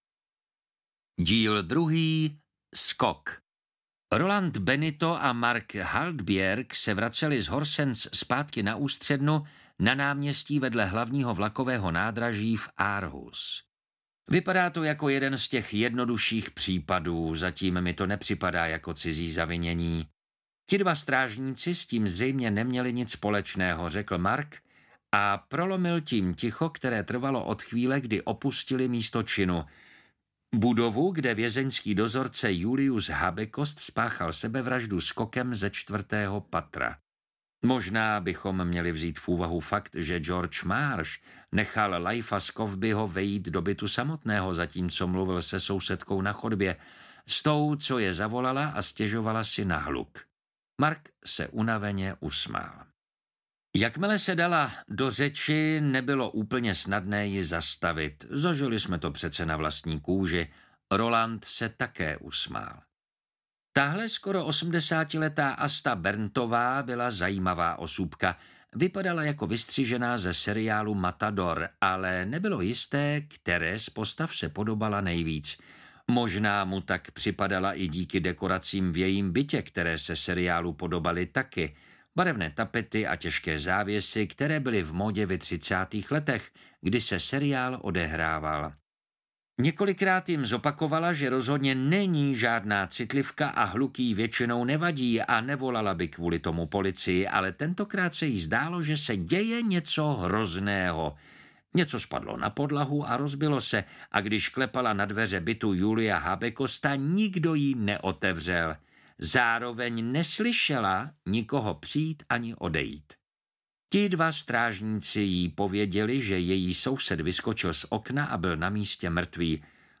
Uklízeč 2: Skok audiokniha
Ukázka z knihy
uklizec-2-skok-audiokniha